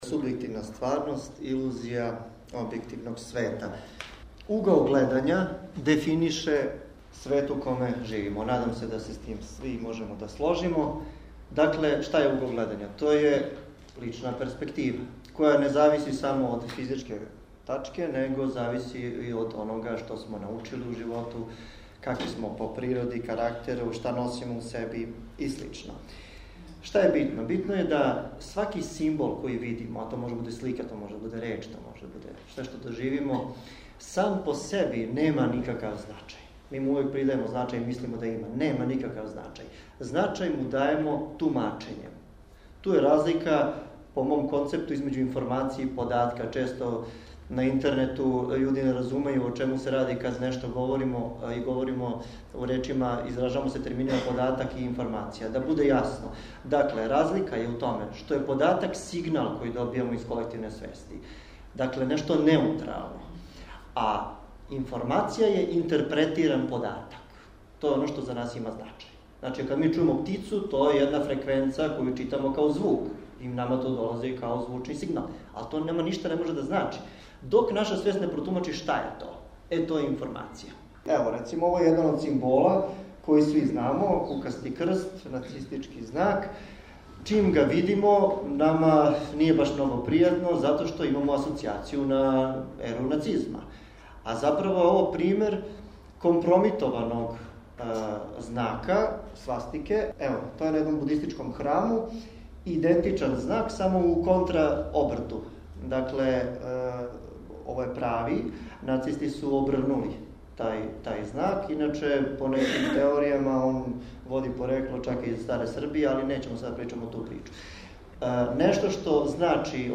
У просторијама Народне библиотеке „Вук Караџић“ одржано је предавање под називом „Теслијанска духовност“.